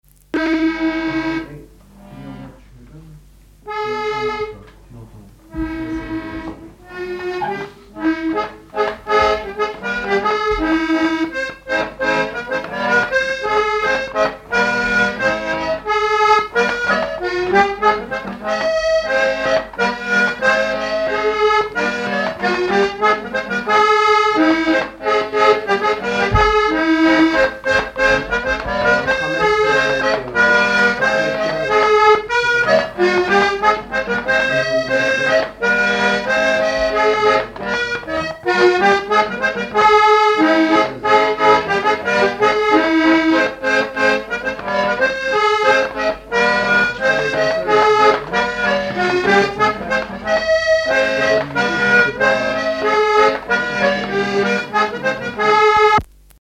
Chants brefs - A danser
danse : scottich sept pas
Enquête Tap Dou Païe et Sounurs, sections d'Arexcpo en Vendée
à l'accordéon diatonique
Pièce musicale inédite